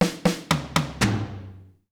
British SKA REGGAE FILL - 04.wav